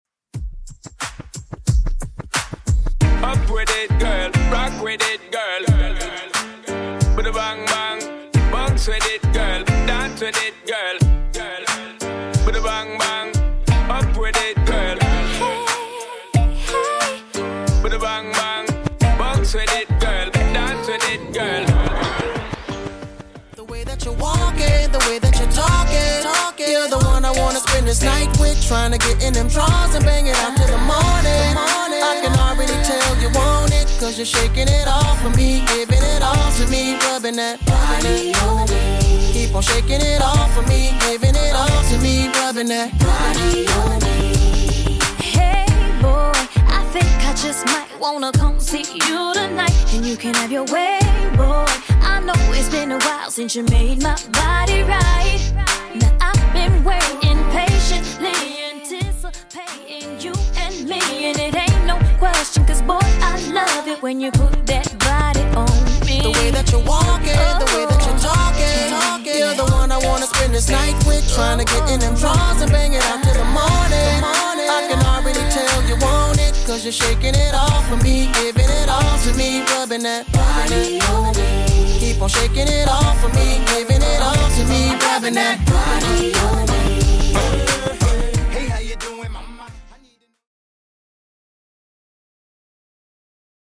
Genre: 90's
BPM: 76